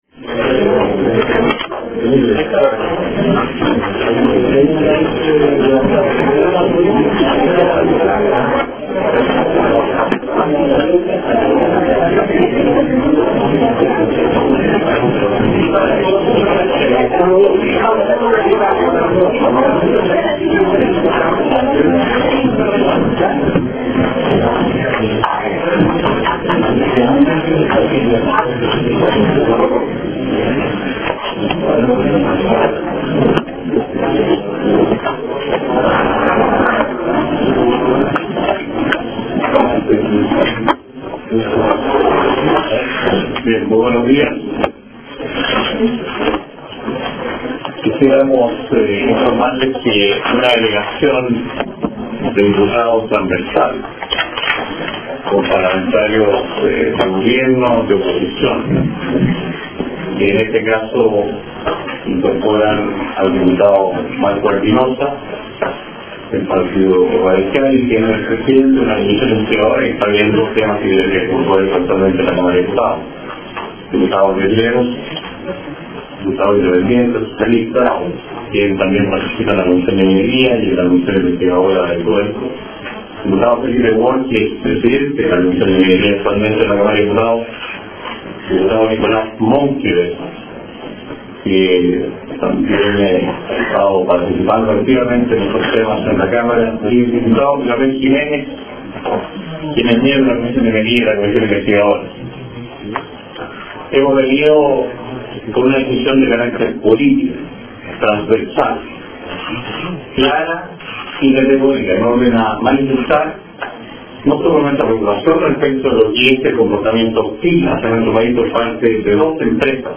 Conferencia de prensa